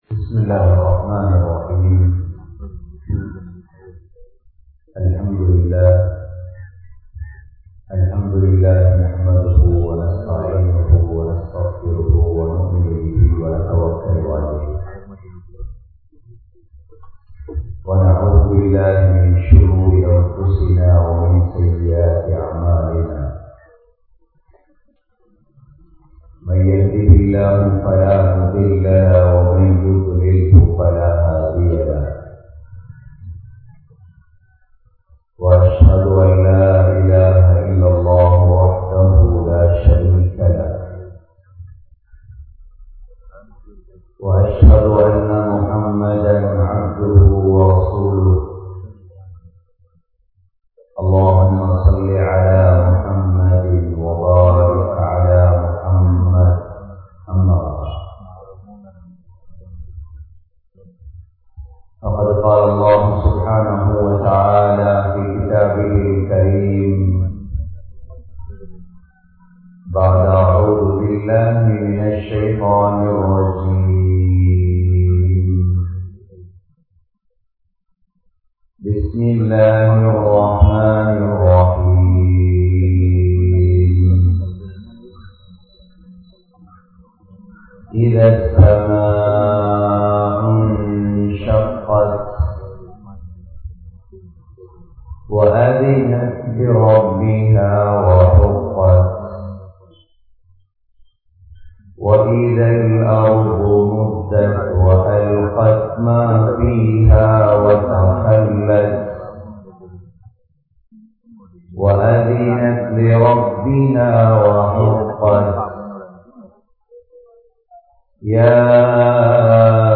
Intha Kaalaththin Theavai (இந்தக் காலத்தின் தேவை) | Audio Bayans | All Ceylon Muslim Youth Community | Addalaichenai